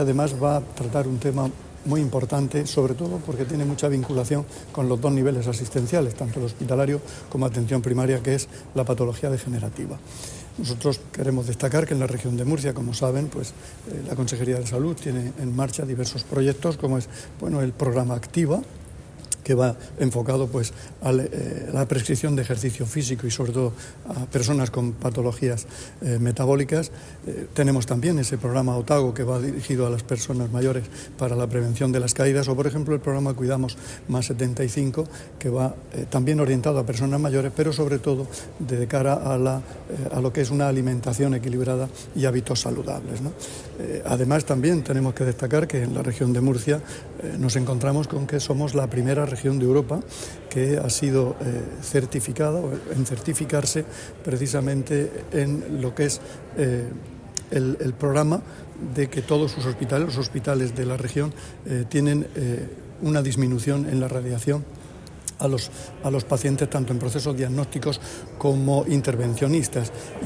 Sonido/ Declaraciones del consejero de Salud, Juan José Pedreño, durante la inauguración  de las XXVI Jornadas de la Sociedad Española de Radiología Musculoesquelética [mp3].